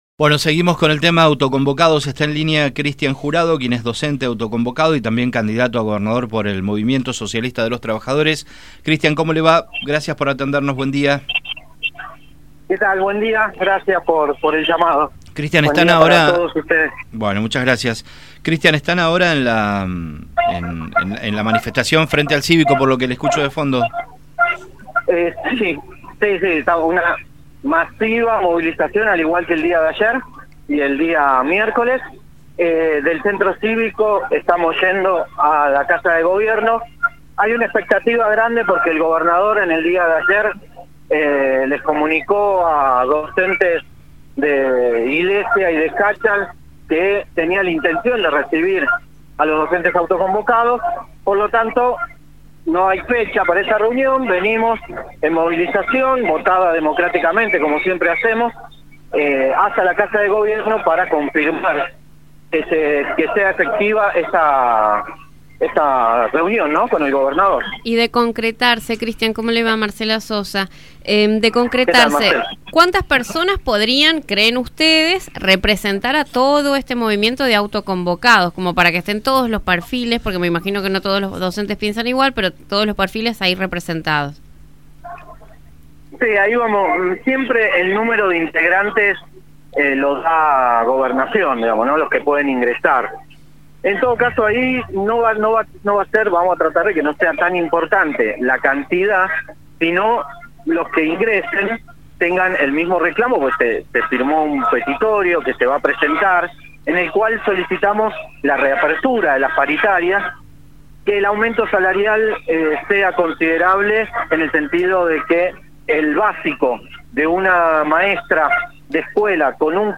estuvo en los micrófonos de Radio Sarmiento para hablar del tema.